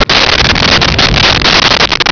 Sfx Crash Jungle B
sfx_crash_jungle_b.wav